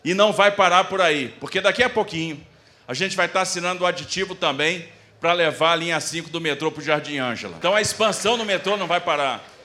Declarações foram feitas pelo Governador Tarcísio de Freitas em cerimônia de assinatura de aditivo para prolongar a linha 4 para Taboão da Serra